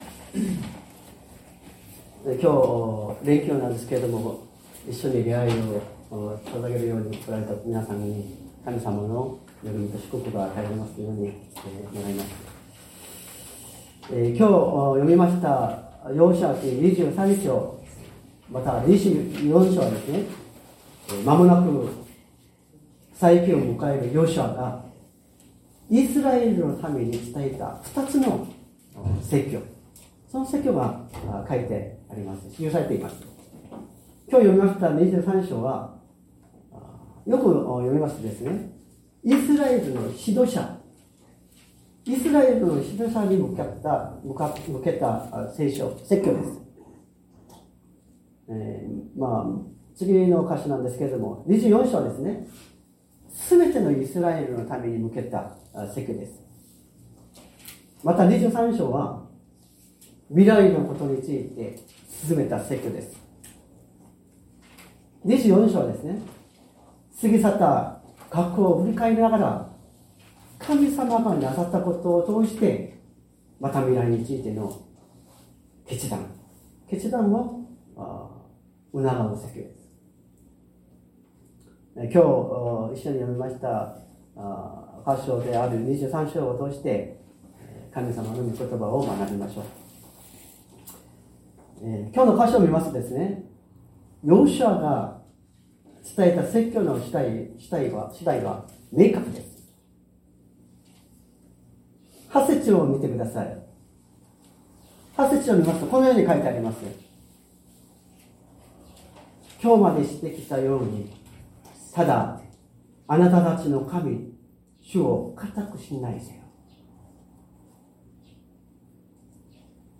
説教アーカイブ 2025年05月05日朝の礼拝「主を愛しなさい」
礼拝説教を録音した音声ファイルを公開しています。